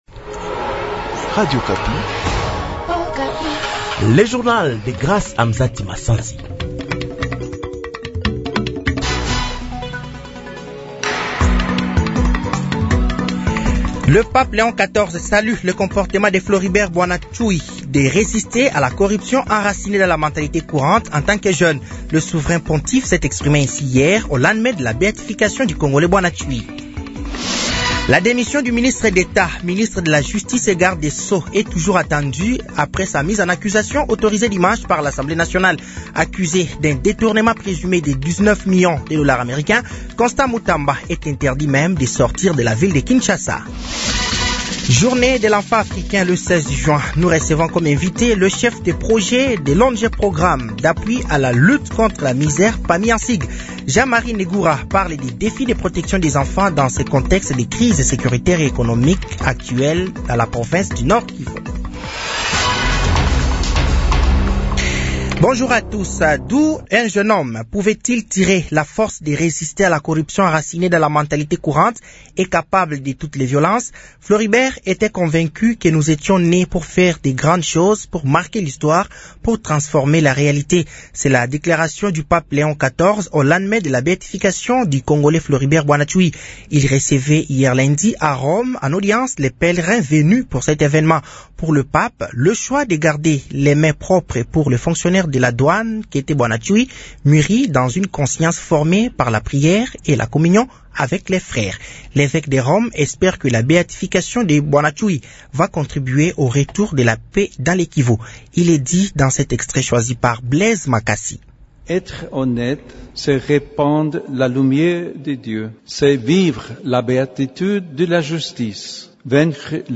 Journal français de 15h de ce mardi 17 juin 2025